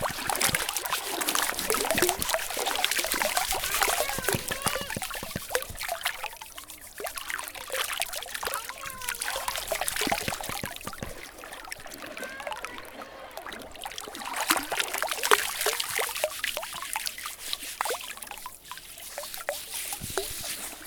물장구.ogg